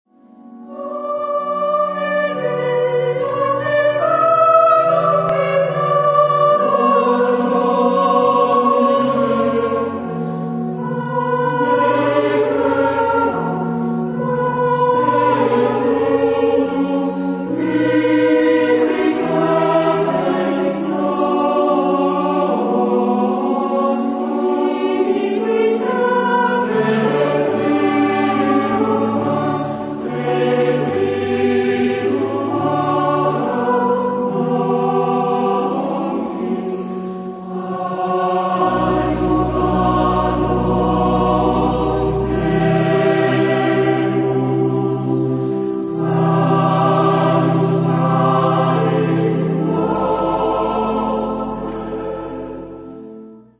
Género/Estilo/Forma: Romántico ; Sagrado ; Motete ; Salmo
Tipo de formación coral: SATB  (4 voces Coro mixto )
Instrumentos: Organo (1) ; Piano (ad lib)